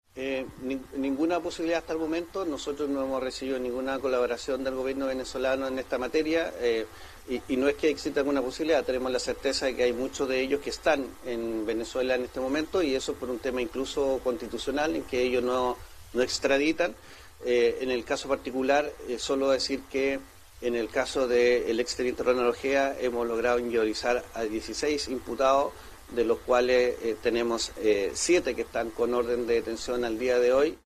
Las declaraciones del fiscal Barros se dieron en el marco de una actividad desarrollada este jueves en la comuna de El Tabo, en la región de Valparaíso.